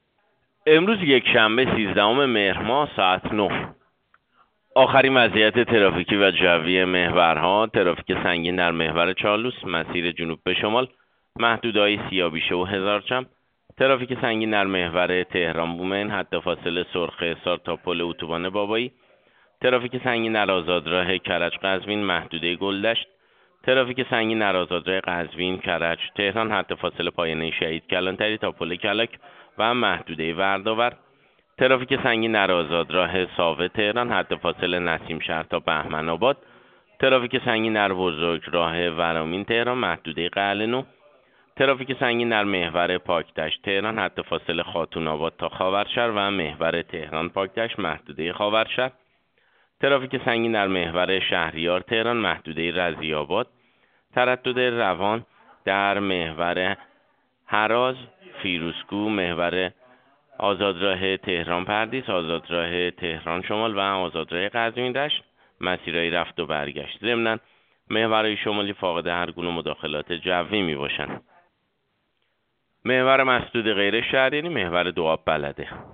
گزارش رادیو اینترنتی پایگاه‌ خبری از آخرین وضعیت آب‌وهوای سیزدهم مهر؛